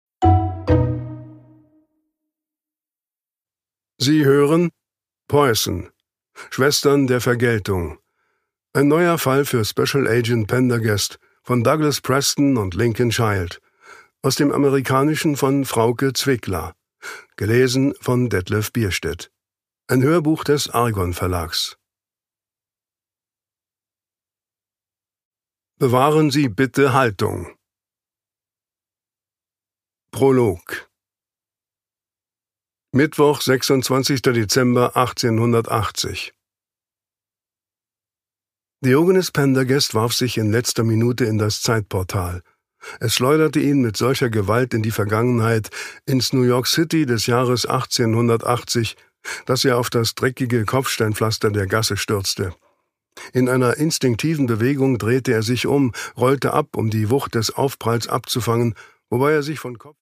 Produkttyp: Hörbuch-Download
Gelesen von: Detlef Bierstedt